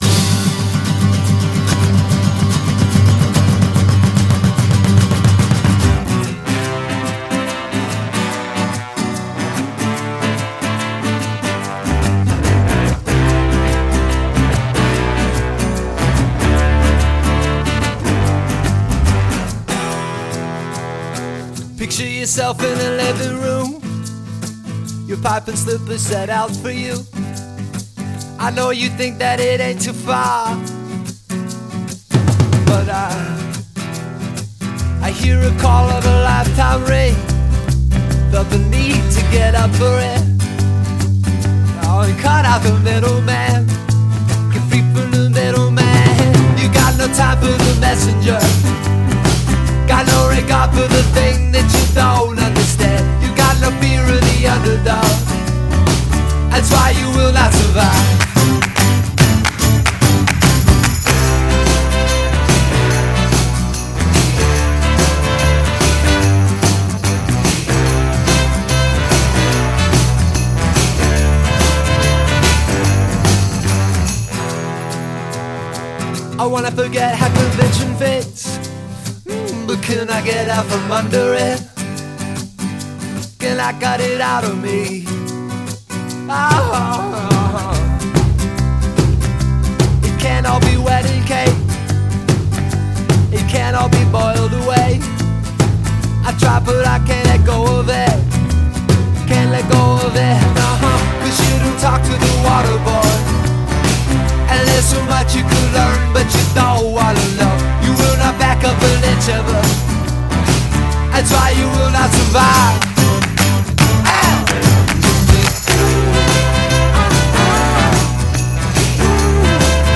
Texas indie poppers
Cautionary anthem